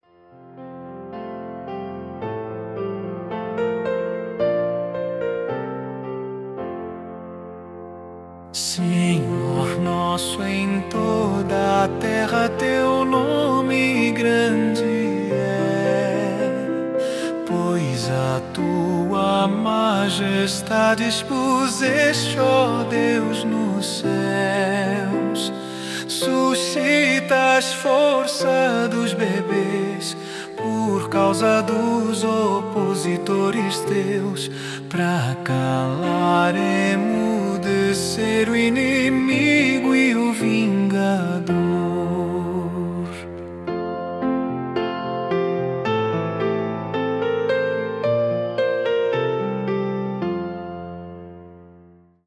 salmo_8B_cantado.mp3